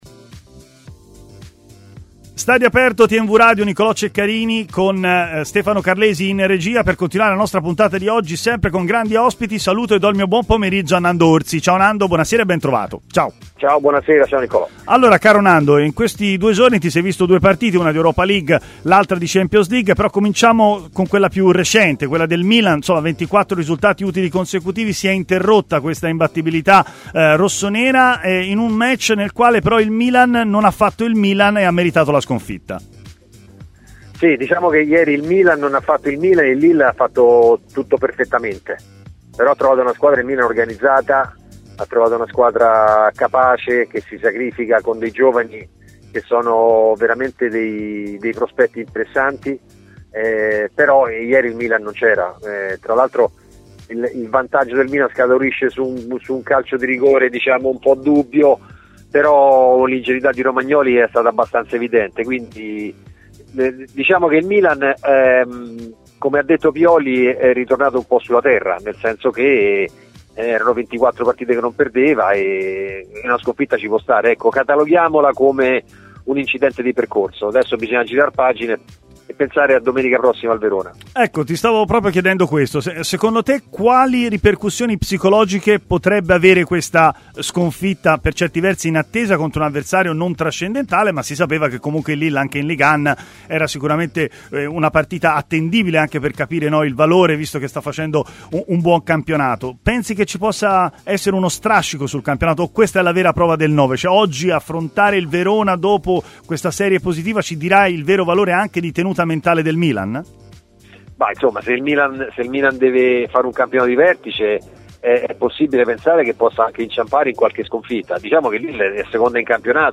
intervenuto in diretta ai microfoni di TMW Radio